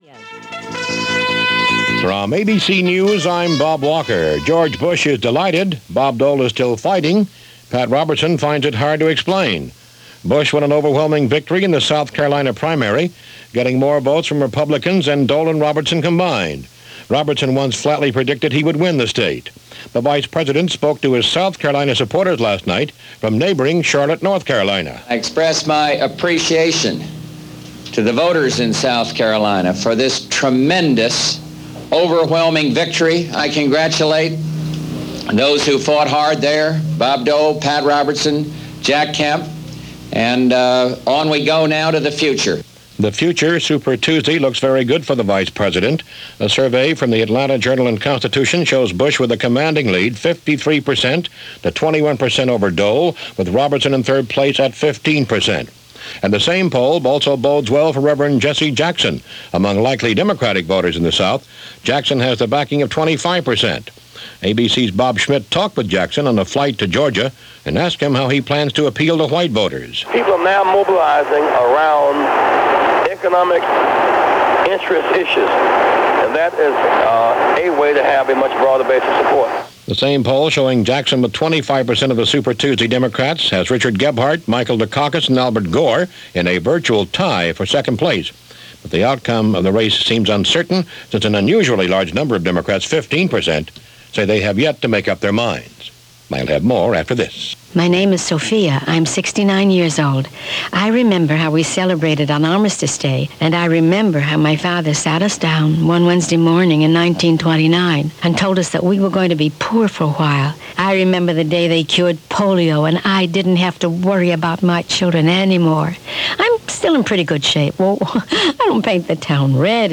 And while Election ’88 rolled on, that’s just a little of what happened, this March 13, 1988 as presented by ABC Radio News.